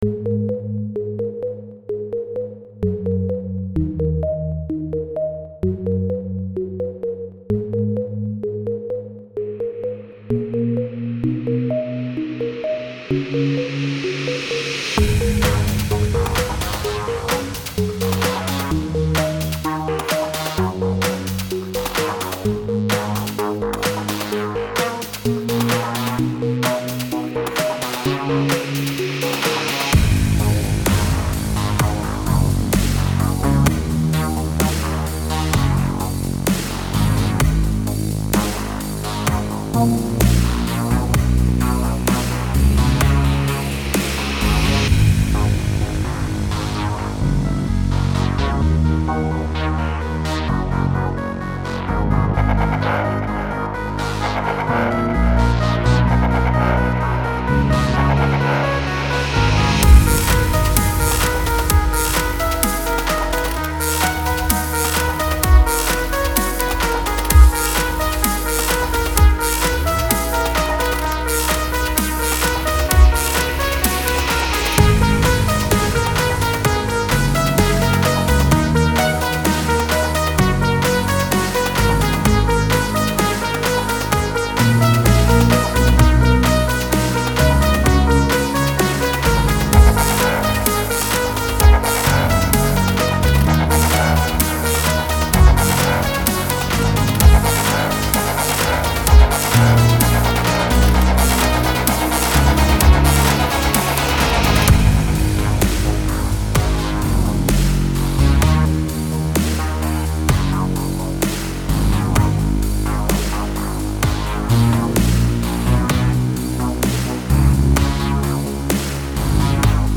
here is Enigma with this new remix . hope you all enjoy it like you enjoy the original song x3 ♥ ♥
techno trance electronic